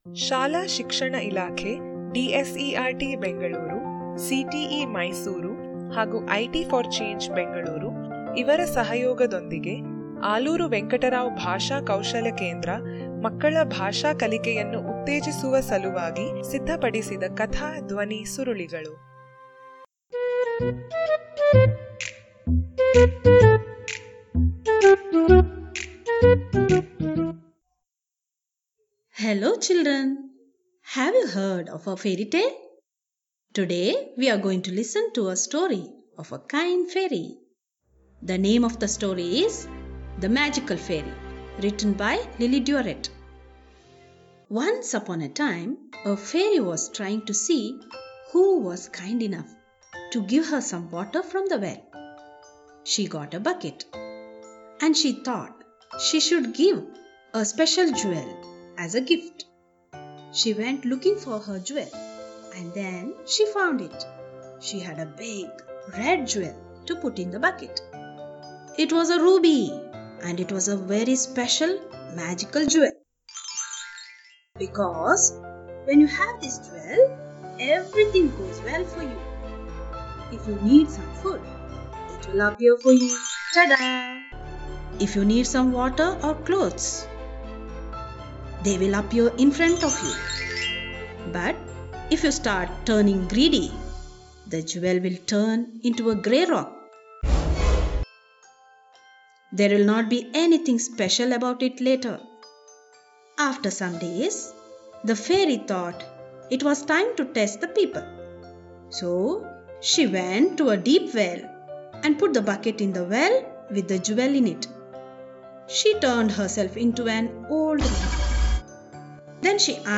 The Magical Fairy - Audio Story Activity Page